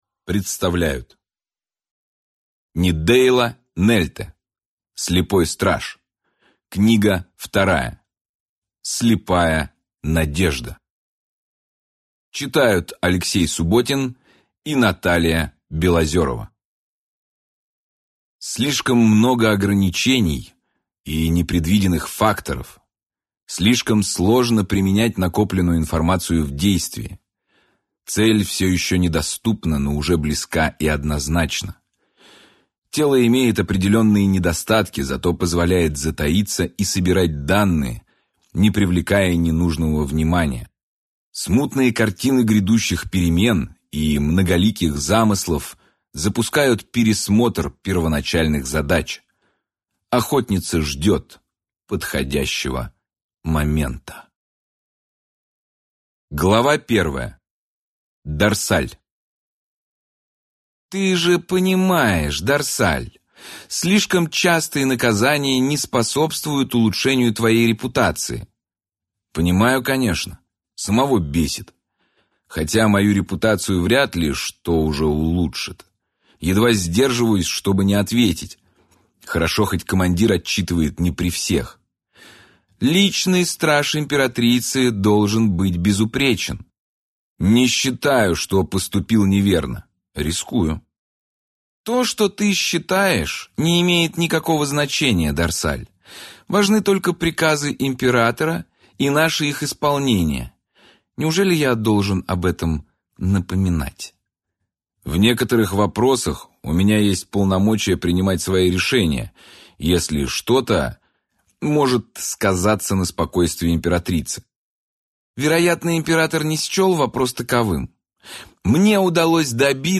Аудиокнига Слепая надежда | Библиотека аудиокниг